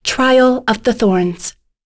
Lorraine-Vox_Skill1_b.wav